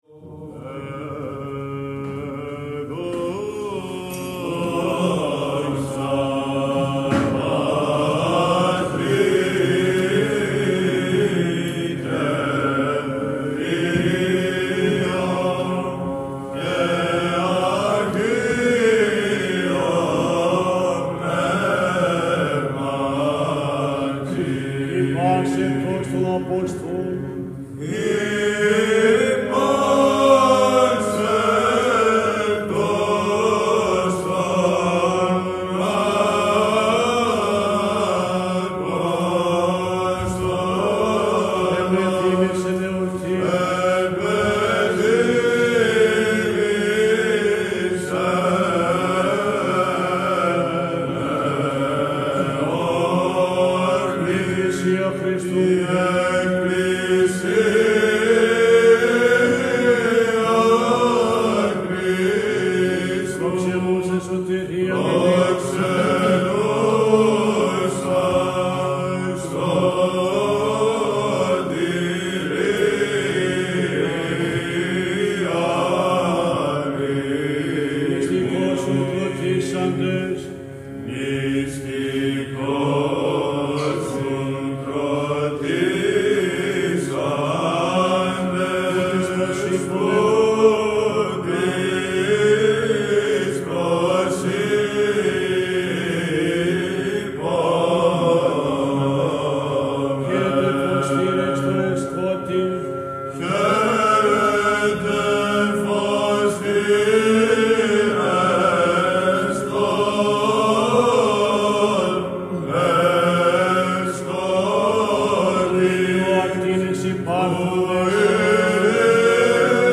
Χορός Βατοπαιδινών πατέρων ψάλει το Δοξαστικό του Όρθρου της εορτής των Αποστόλων Πέτρου και Παύλου »Η πάσνεπτος των Αποστόλων», σε ήχο πλ. του β΄, ποιήμα Κοσμά Μοναχού και μέλος Στεφάνου Λαμπαδαρίου.